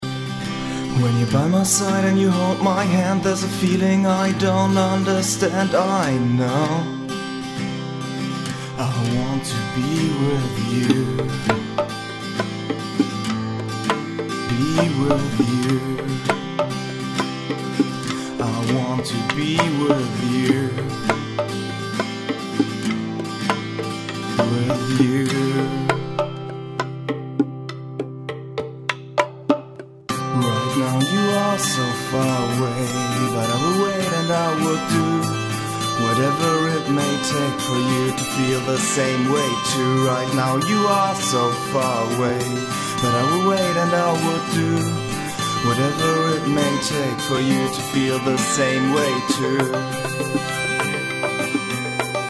Akustik Gitarren Song Right Now Feedback
Per Hand gespielt hab ich die Percussion nicht, habe dafür auch weder know how noch Instrumente zur Verfügung. Ich hab mal die Percussion halb so schnell gestellt....